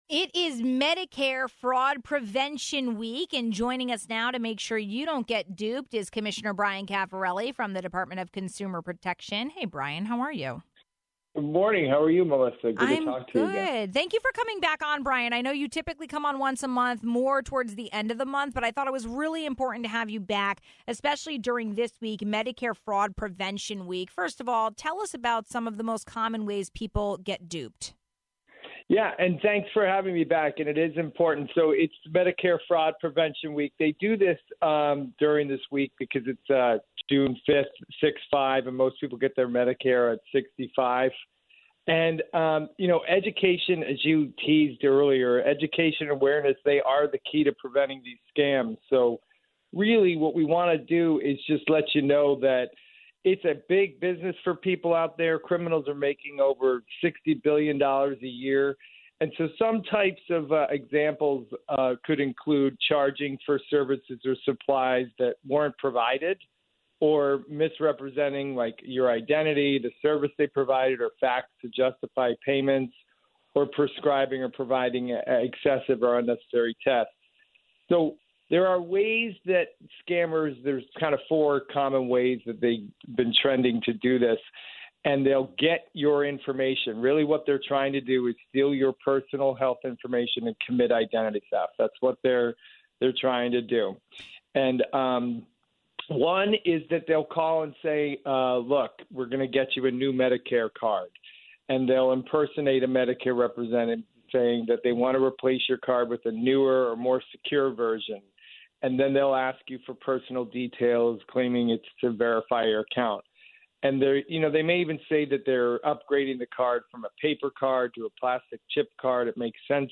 This week is Medicare Fraud Prevention Week, and we spoke with Dept. of Consumer Protection Commissioner Bryan Cafferelli about identifying this scam and avoiding being duped by fraudsters.